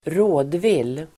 Ladda ner uttalet
rådvill adjektiv, perplexed , irresolute , at a loss Uttal: [²r'å:dvil:]